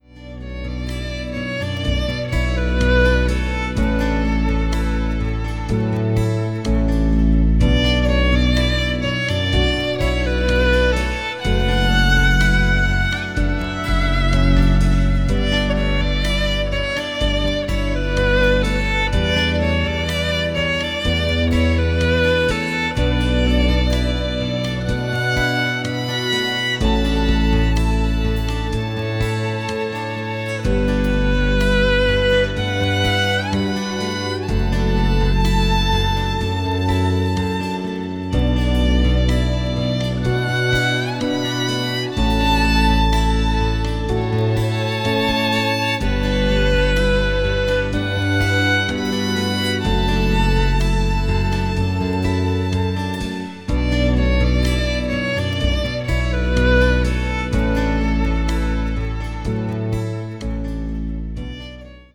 Recorded at the Royal Botanic gardens